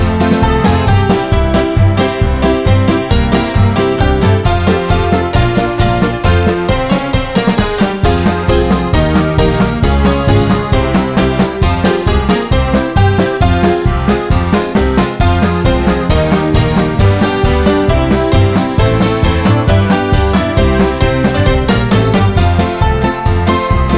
Easy Listening